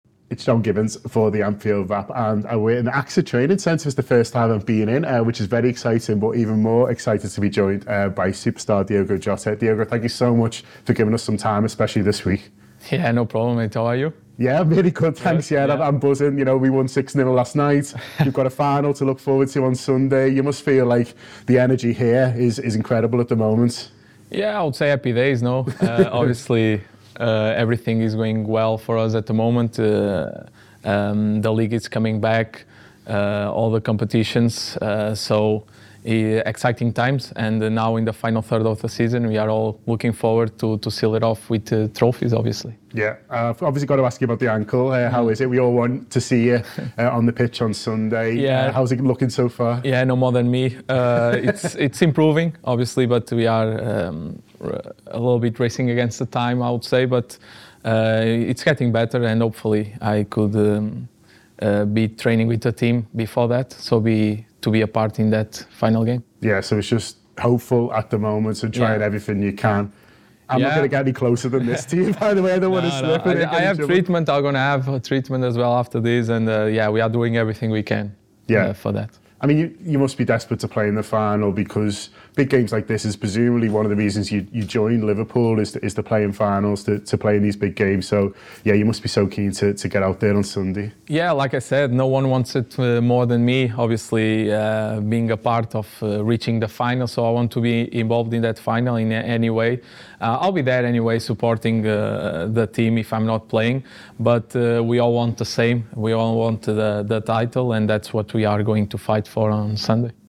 Diogo Jota speaks exclusively to The Anfield Wrap in an interview before the League Cup final between Liverpool and Chelsea at Wembley.